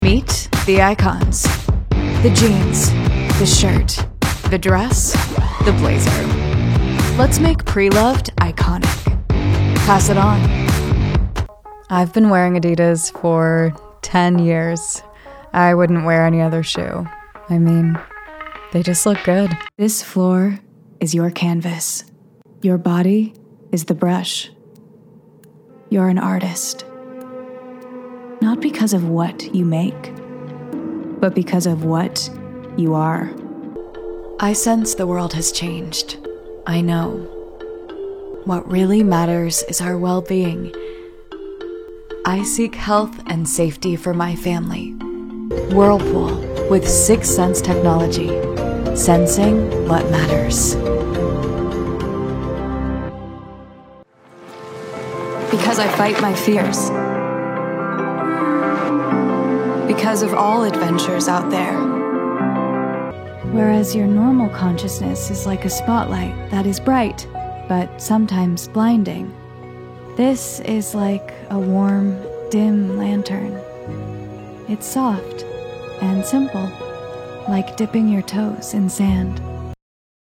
Edgy, Poetic Reel